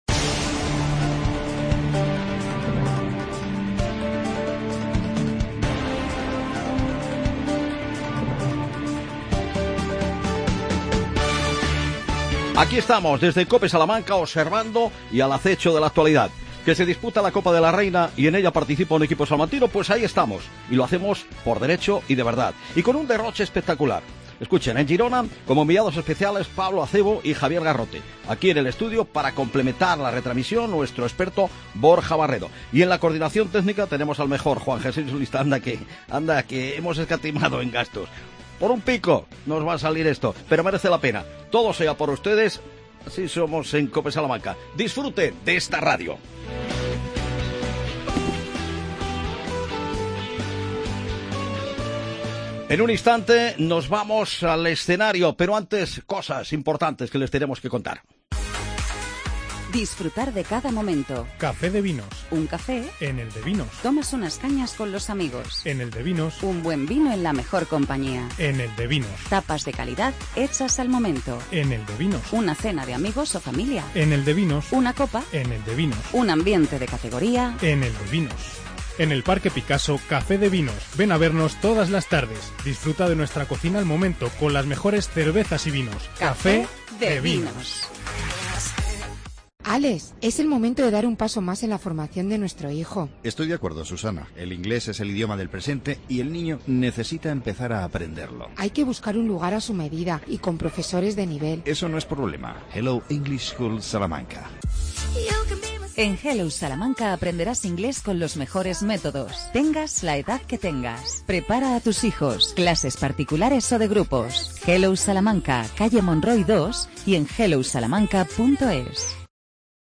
AUDIO: Retransmisión completa de la semifinal de la Copa de la Reina '17: Perfumerías Avenida - Gernika.